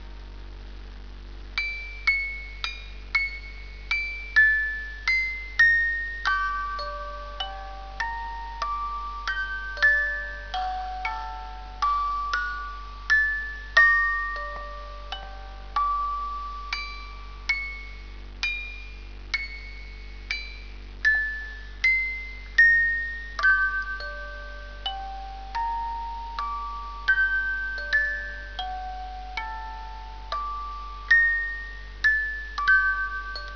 • opwindbare ronddraaiende hand
• speelt op eigen wijze "Fur Elise"